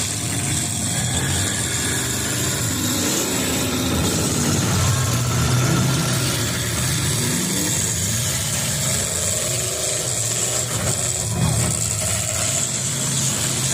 Once again the fair wrapped up Saturday night with the annual Smashin, Crashin and Bashin Demolition Derby inside of the Lyon County Fairgrounds grandstands arena!
3072-demo-derby-2.wav